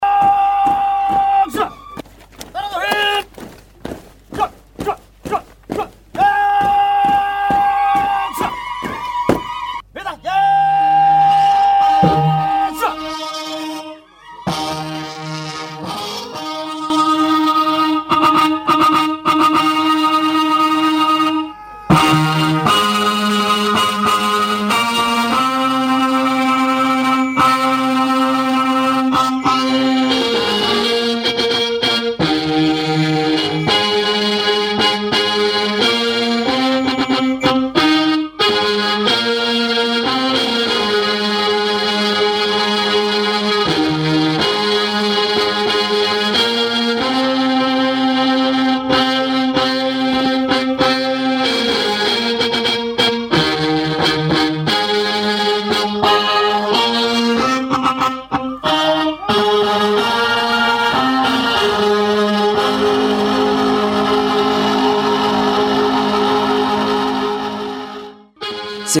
Xaflada Waxa jooga Wasiiro Ka Tirsan Dowladda KGS, Odayaasha Dhaqanka iyo Ururka Haweenka ee Degmada Baydhabo ,Saraakiisha Ciidanka Dowladda Soomaaliya Iyo Ciidamada AMISOM.
Mas’uulyii kala Duwan ayaa Khadbado Ka Jeediyey Munaasabadda waxaa halkaasi laga Akhriyey Halgankii Dheeree Ee Umadda Soomaaliyeed u soo Gashey in Ka Xurowdo Gumeysiga Dowladda Taliyaaniga isla markaana waxa la   Xusey Magacayo Halgamiyaal Soomaaliyeed ah.